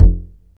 Bass (12).wav